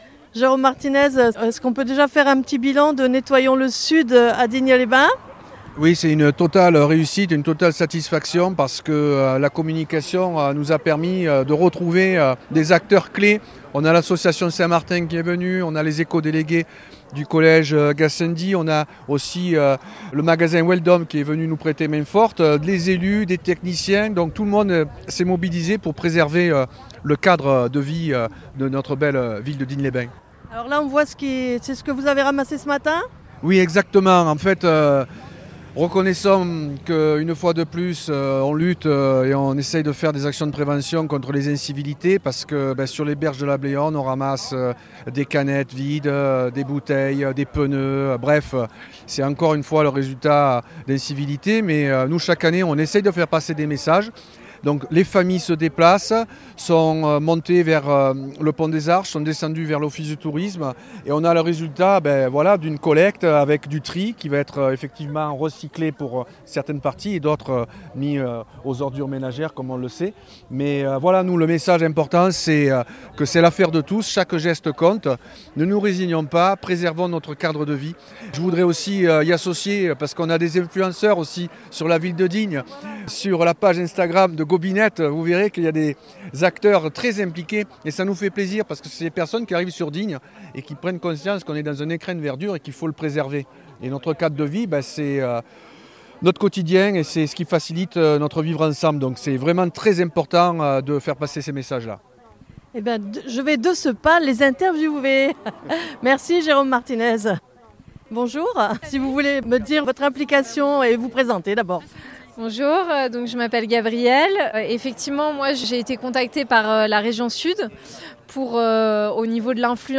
Jérôme Martinez Conseiller municipal à la transition écologique et économie sociale et solidaire à Digne les Bains